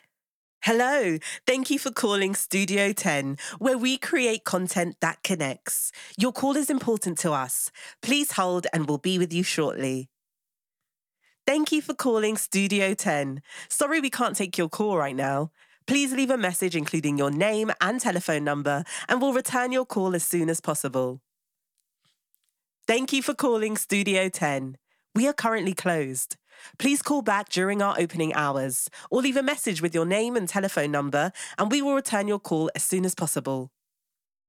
Female
Phone Greetings / On Hold
All our voice actors have professional broadcast quality recording studios.